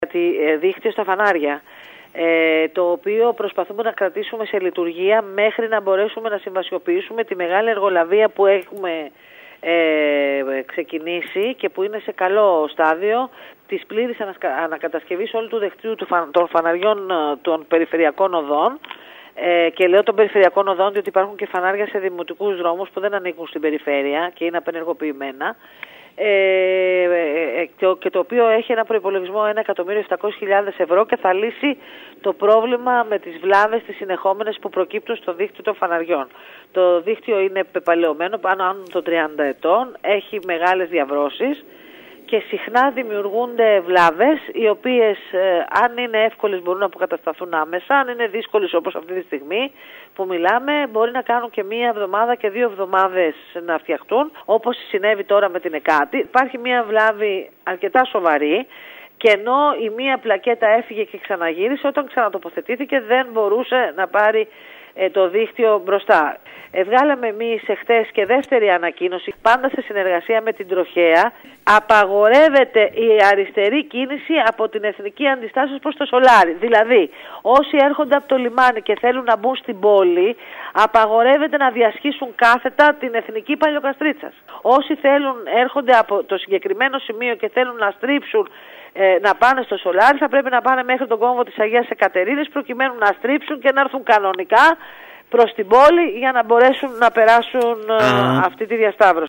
Η Αντιπεριφερειάρχης Κέρκυρας Μελίτα Ανδριώτη, μιλώντας στο σταθμό μας, αναφέρθηκε στα κυκλοφοριακά μέτρα που έχουν ληφθεί στον κόμβο της Εκάτης εξαιτίας της σοβαρής βλάβης στους ηλεκτρικούς σηματοδότες. Οι οδηγοί θα πρέπει να είναι ιδιαίτερα προσεκτικοί αφού πλέον απαγορεύεται σε αυτούς που έρχονται από το εμπορικό κέντρο να στρίβουν αριστερά προς το Σολάρι.